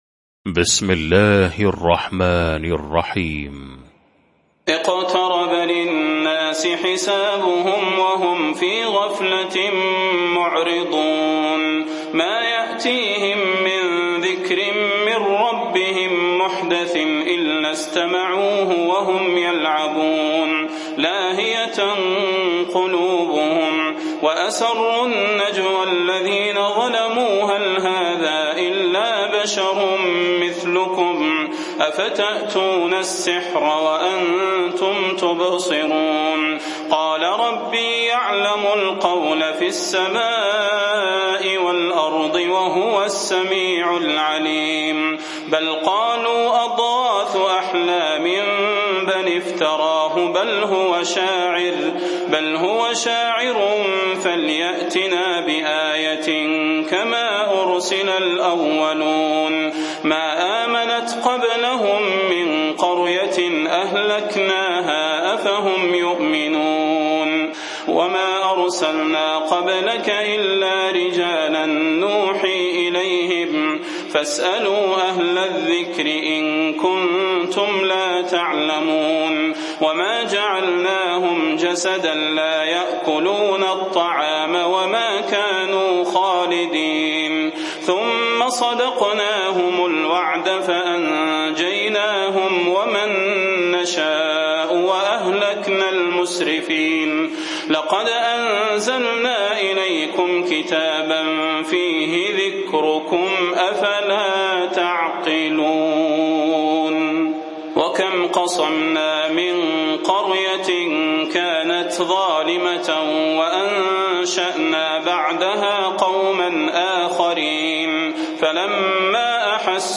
المكان: المسجد النبوي الشيخ: فضيلة الشيخ د. صلاح بن محمد البدير فضيلة الشيخ د. صلاح بن محمد البدير الأنبياء